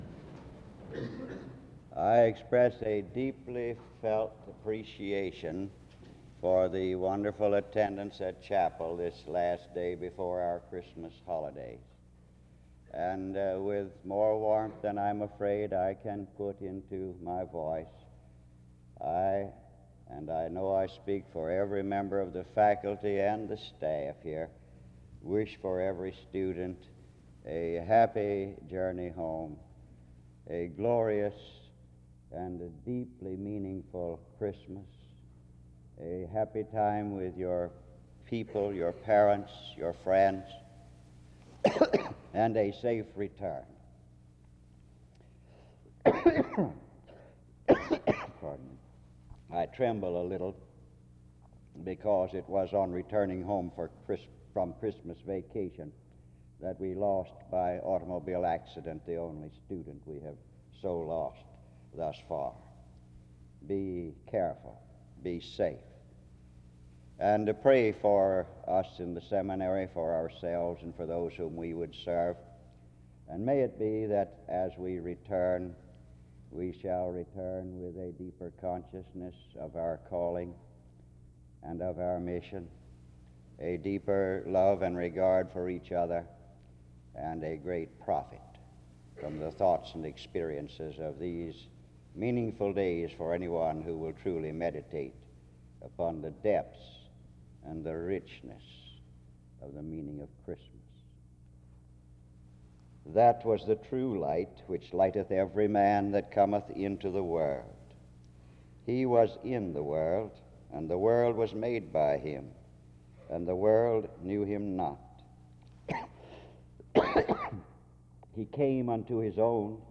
In Collection: SEBTS Chapel and Special Event Recordings SEBTS Chapel and Special Event Recordings